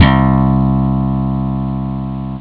FUNKYG SlapBass.wav